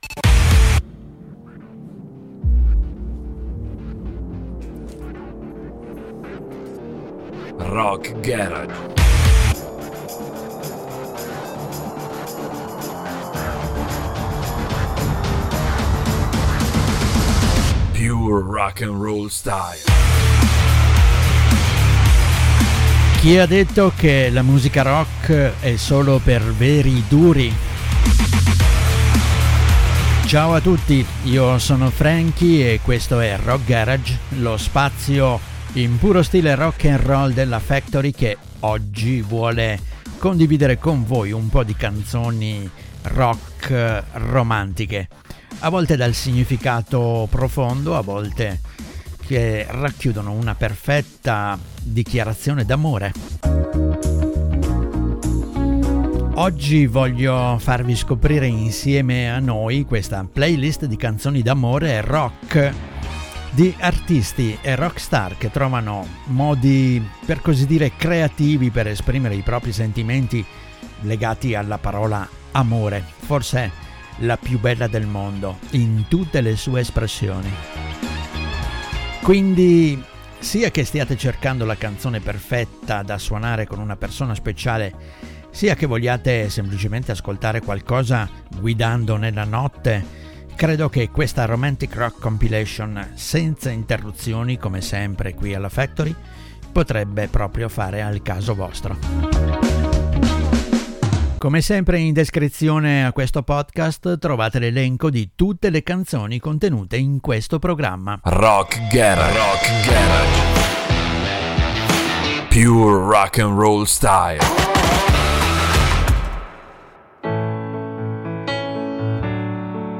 Ma chi l’ha detto che la musica rock è solo per veri duri?